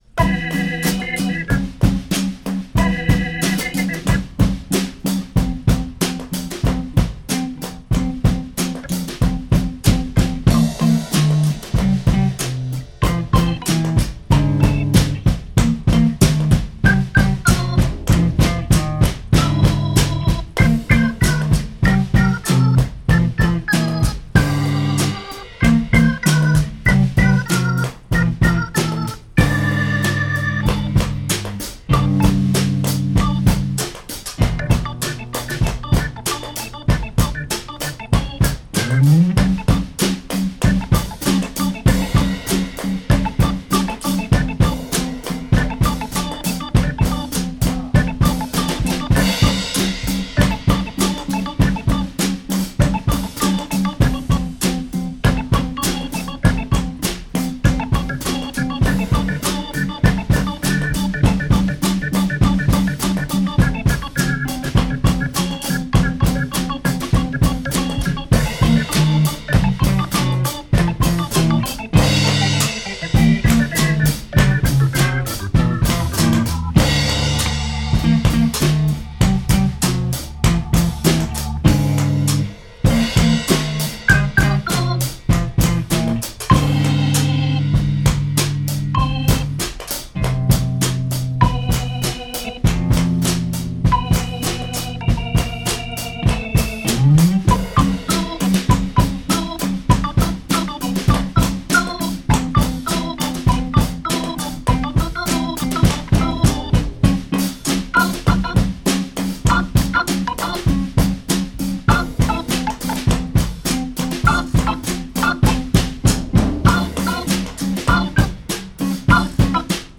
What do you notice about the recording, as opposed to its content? Recorded live at the Maid’s Room, New York City Stereo (Pro Tools)